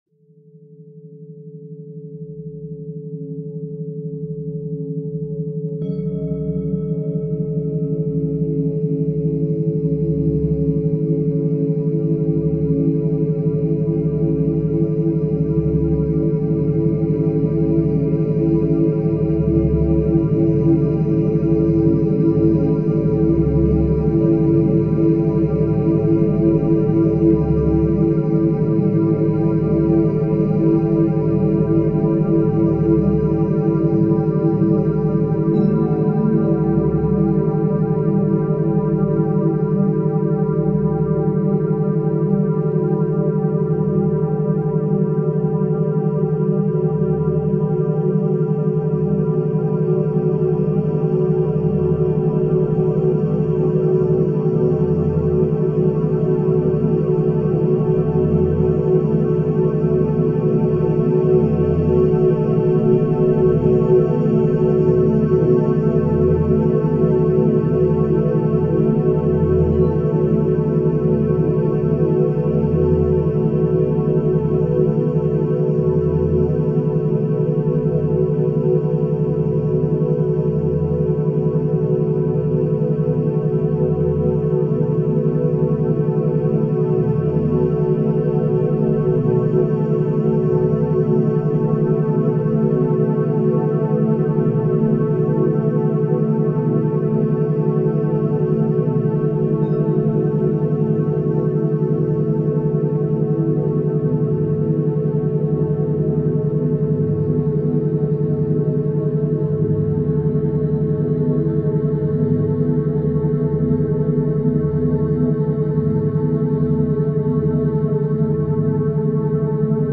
Earth Meditation – 7.83 Hz Schumann Resonance for Deep Grounding